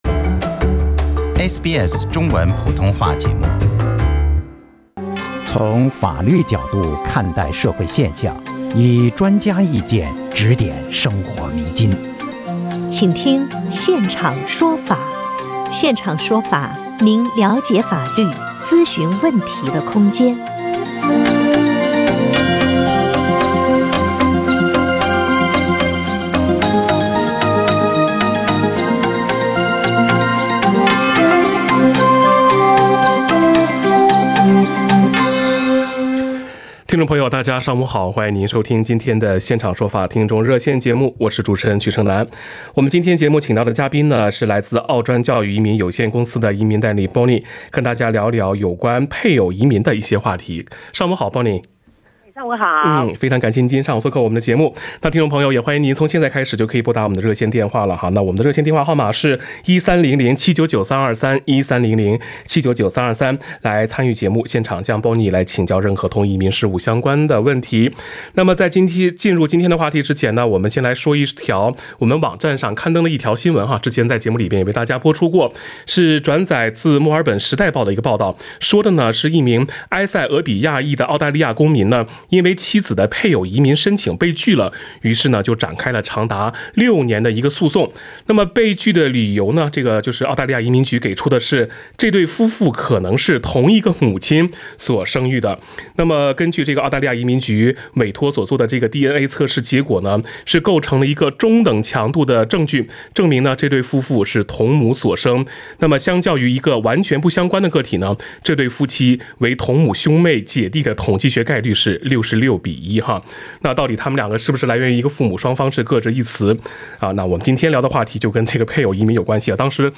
《现场说法》听众热线逢周二上午8点30分至9点播出。